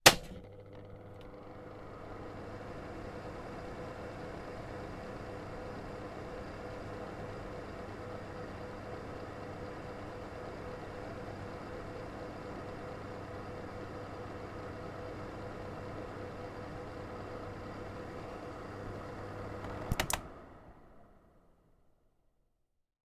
На этой странице собраны звуки работающего очистителя воздуха – монотонный белый шум, напоминающий легкий ветер.
Звук включения и выключения очистителя воздуха